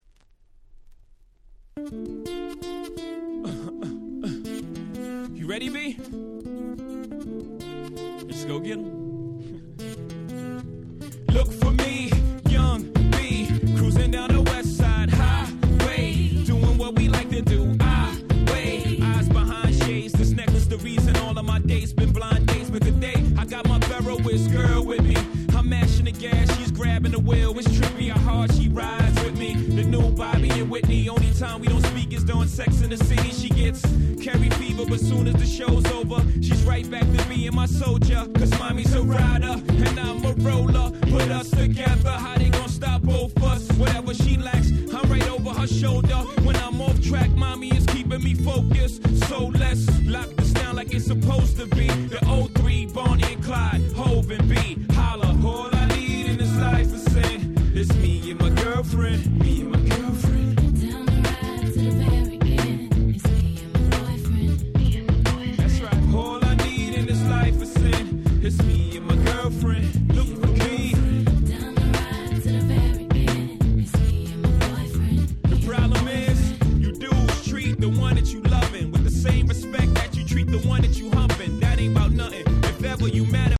02' Super Hit Hip Hop !!